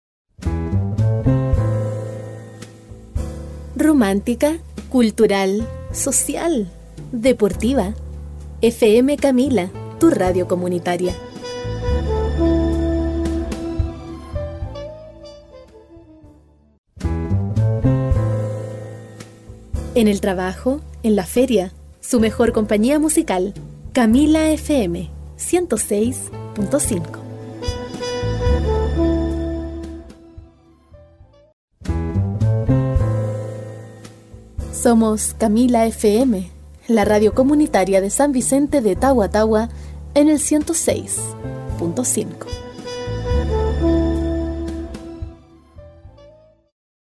Español Neutro (latino) Noticias Publicidad Voz ancla (anchor voice) E-Learning
Kein Dialekt
Sprechprobe: Werbung (Muttersprache):